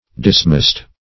Search Result for " dismast" : The Collaborative International Dictionary of English v.0.48: Dismast \Dis*mast"\, v. t. [imp.